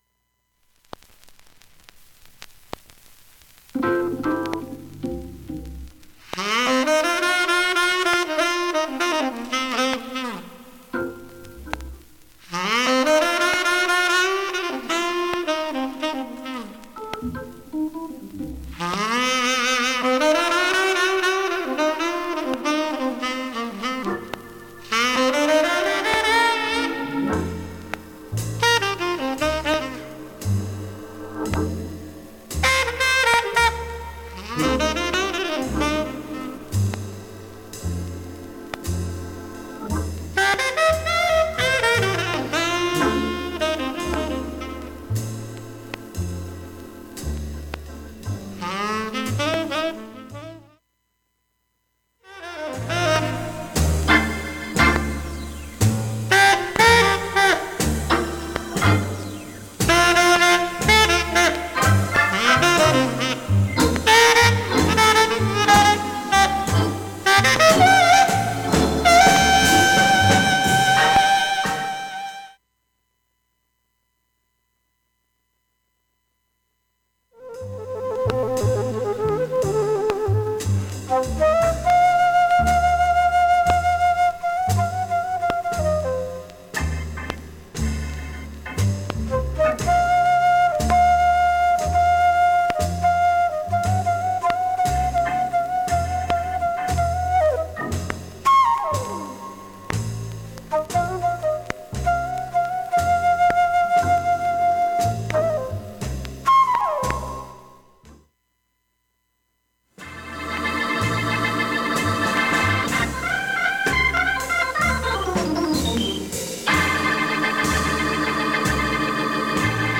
音質良好全曲試聴済み。
ほとんどが周回プツ音が出ています。
それ以外はプツもチリなどもありません。